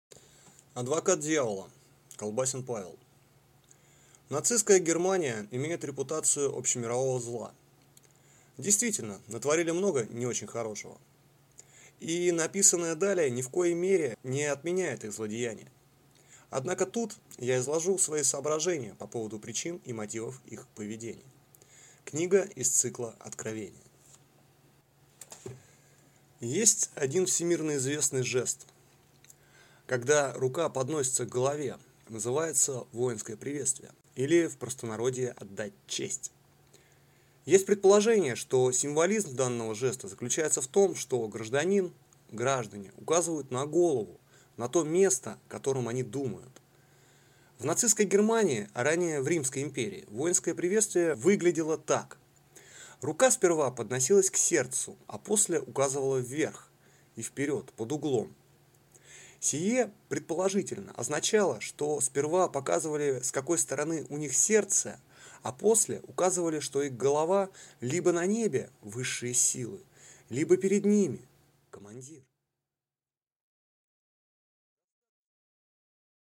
Аудиокнига Адвокат Дьявола | Библиотека аудиокниг